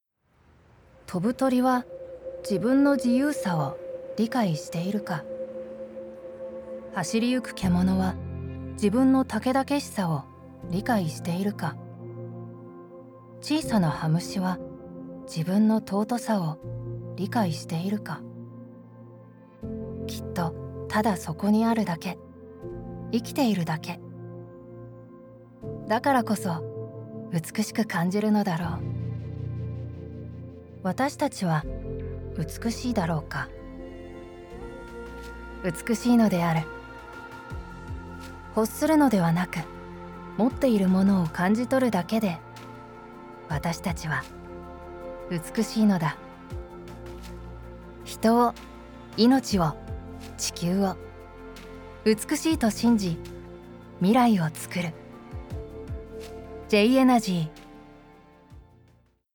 ナチュラル＿モノローグ_壮大　エネルギー企業CM